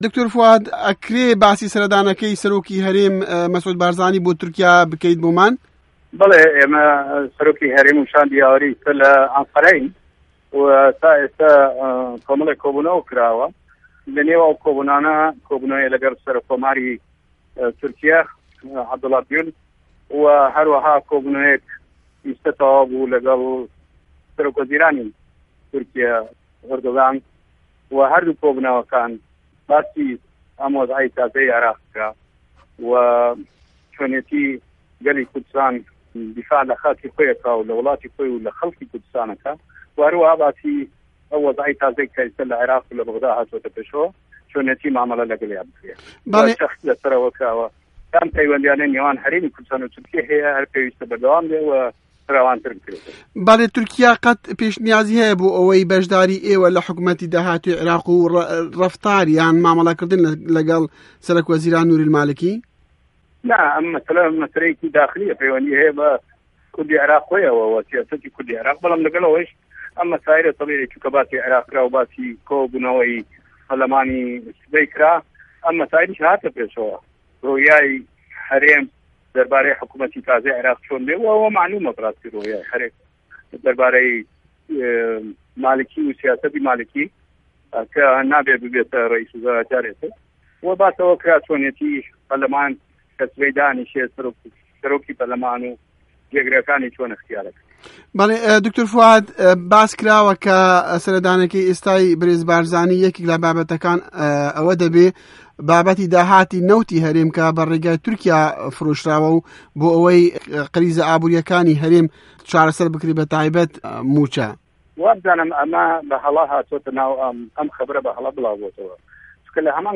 وتووێژی دکتۆر فوئاد حسێن